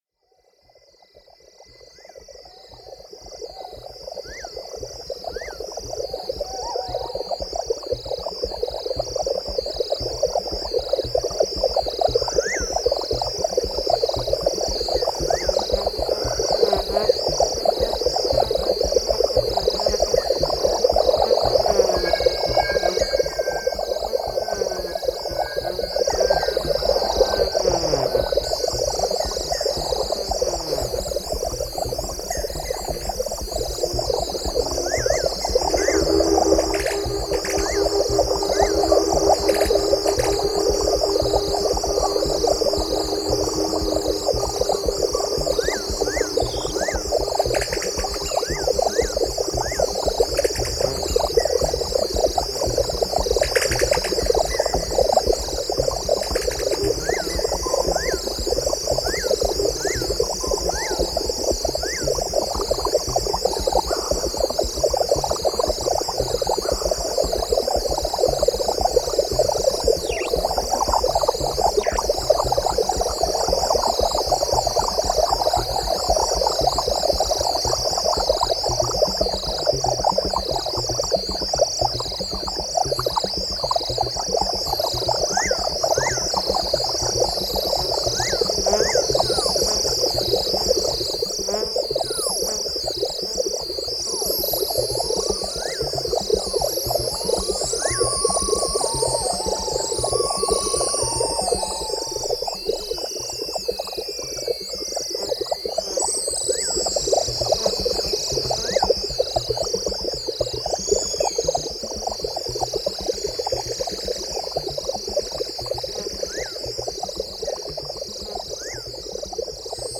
The complete set of my modified Synthi A TKS